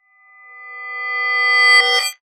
time_warp_reverse_spell_02.wav